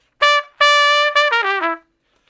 084 Trump Shuffle (E) 07.wav